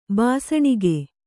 ♪ bāsaṇige